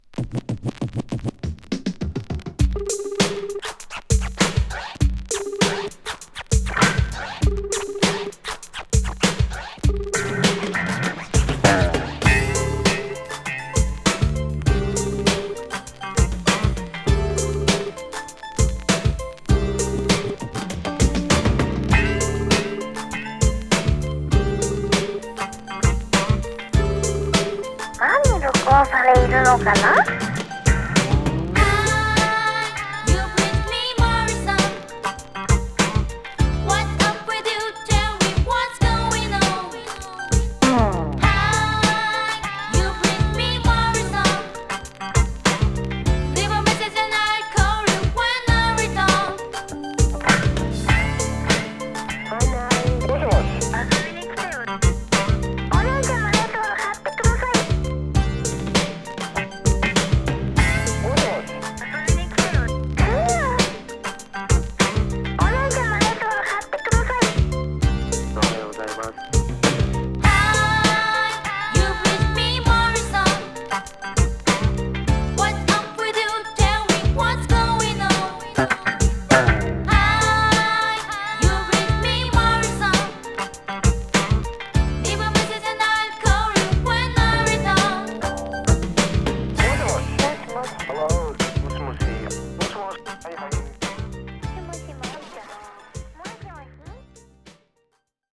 バラード、ポップ、ロック調のものまで、バラエティに富んでいる。